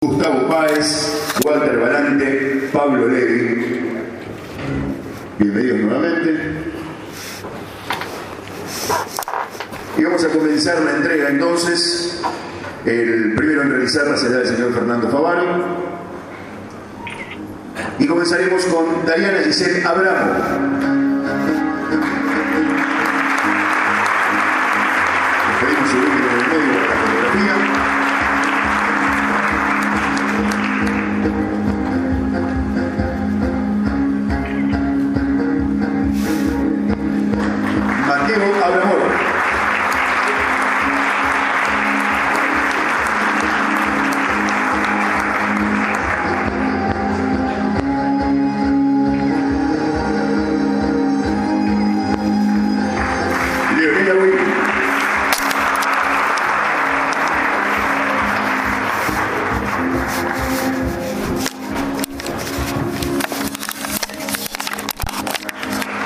Los días miércoles 5 de julio y jueves 6 de julio se realizó en el Teatro Municipal de San Nicolás los actos donde se entregaron las Becas al Mérito que otorga la Fundación Hermanos Agustín y Enrique Rocca.
Audio: Entrega de Becas